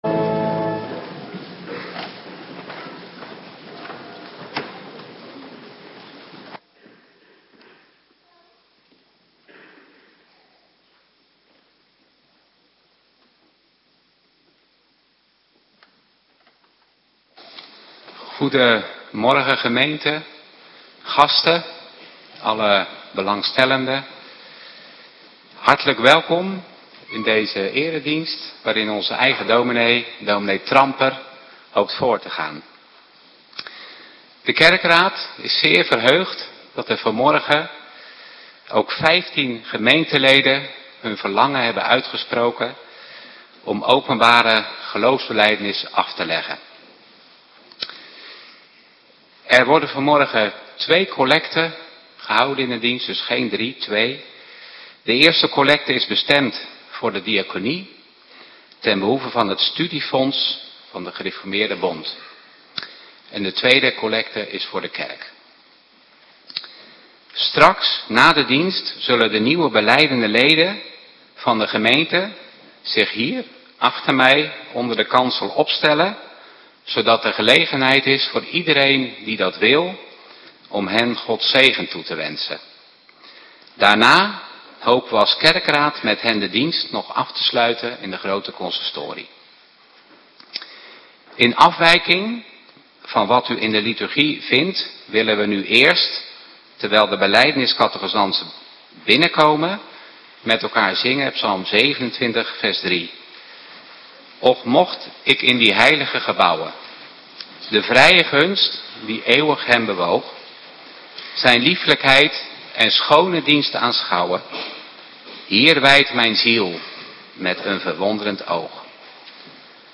Belijdenis dienst Ps 27 . 3 en 5 Ps 16 . 4 2 Tim 2 . 1/13 Tekst vers 8 Thema :Opzien naar de Opgestane ?